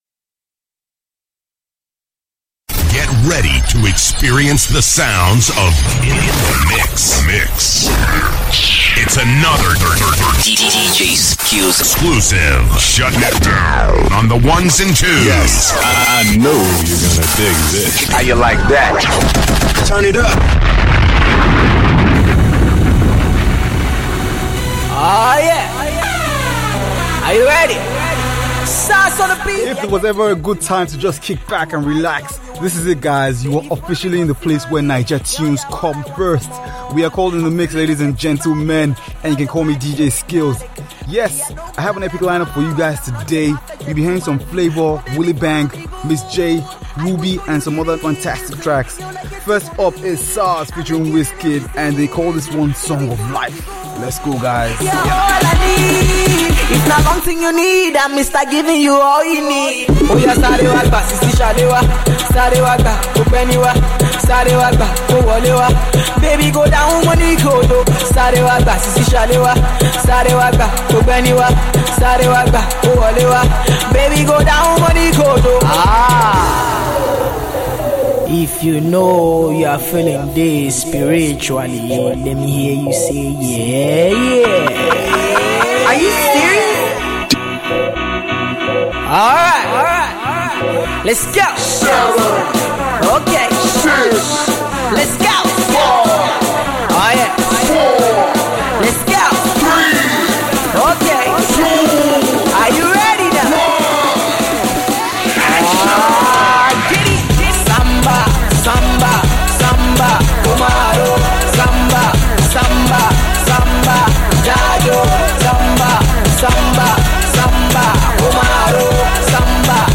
we are the place where Naija music comes 1st